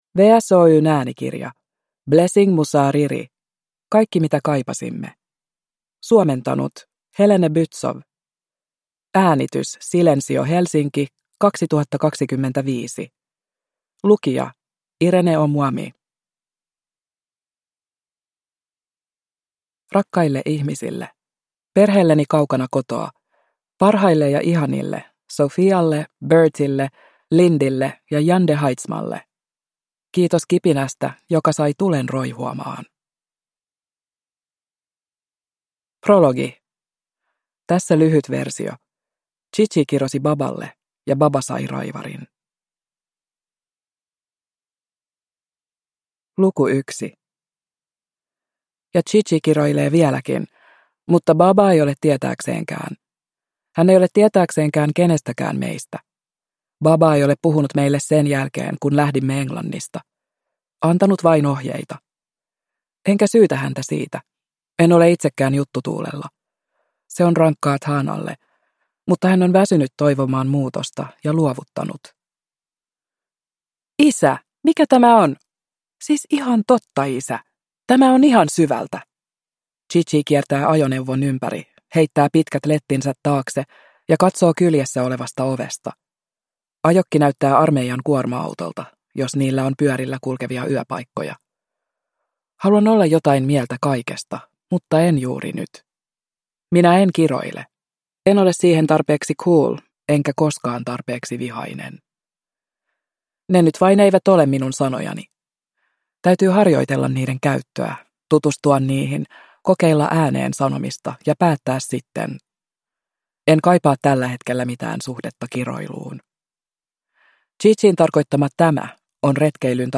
Kaikki mitä kaipasimme – Ljudbok